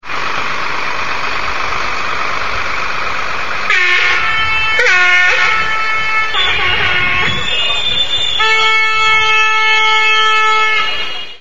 Agricultorii ialomiteni si-au scos tractoarele din brazda si au venit sa protesteze in fata Prefecturii, incercand astfel să obtina sustinerea autoritătilor judetene in demersurile catre ministerul agriculturii si guvernului Romaniei.
audio-tractoare.mp3